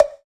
SOUTHSIDE_percussion_dok.wav